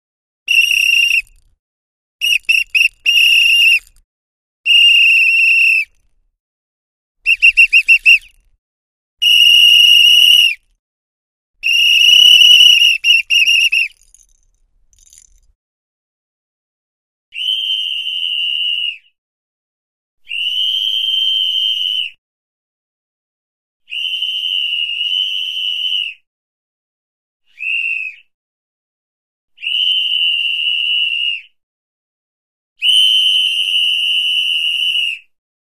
Звуки свиста и свистков
1. Свисток судьи:
zvuk-svistka-sudi.mp3